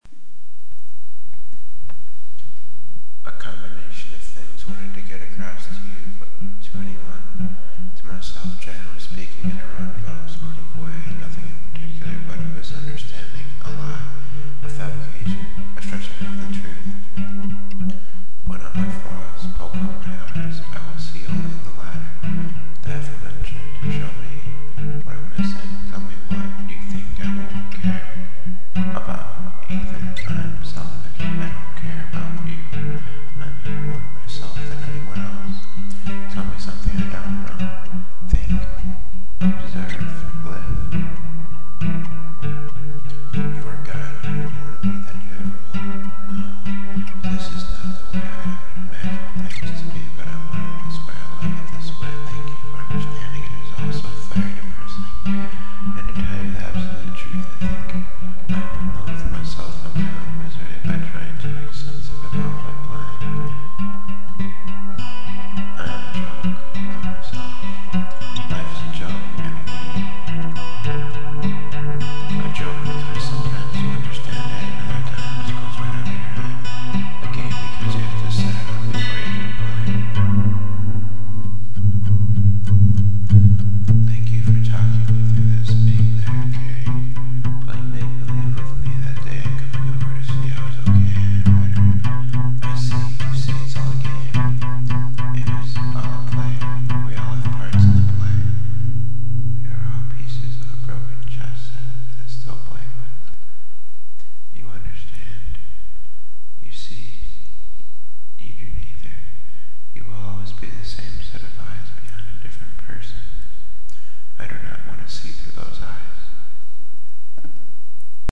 a song i made in 5 minutes. spoken word.